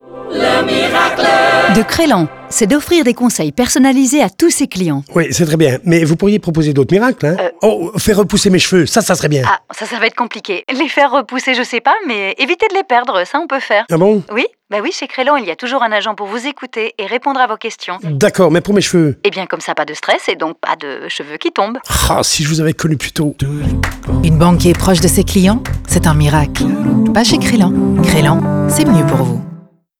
Chaque spot donne la parole à une personne stupéfaite par le miracle, qui cherche aussitôt à le partager avec une autre personne dans le spot suivant. Après une semaine, deux spots plus classiques de 30 secondes prennent le relais.